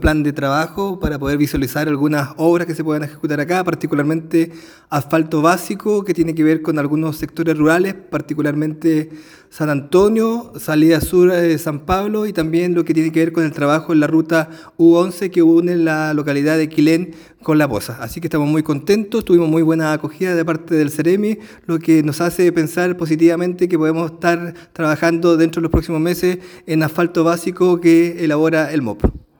Durante el encuentro, se discutieron iniciativas fundamentales para el desarrollo de la comuna, entre ellas el asfaltado básico en sectores como San Antonio, la salida sur de San Pablo , y la Ruta U-11, que conecta Quilén con La Poza, entre otras según explicó el Alcalde Carrillo.